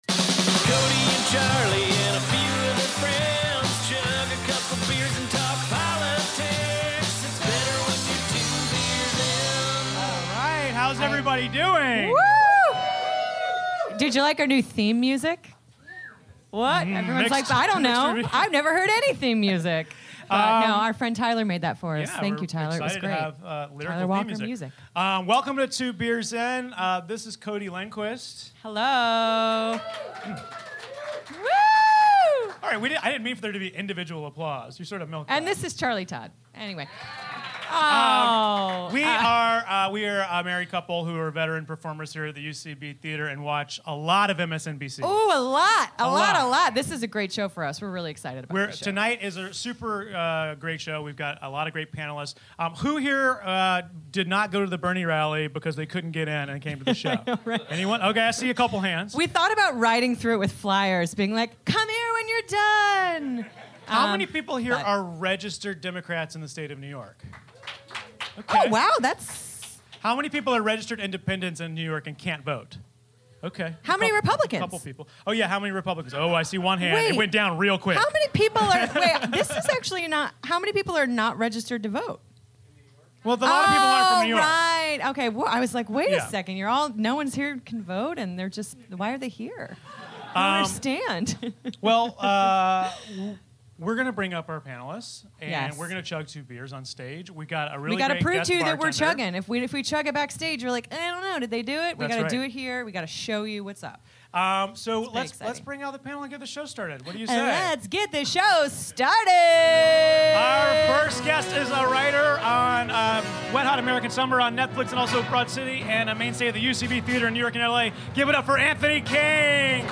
We chug two beers and talk telestrator dongs, the First Gentleman, and Trump's Jock Jams. Recorded live from the UCB Theatre East Village on April 13, 2016.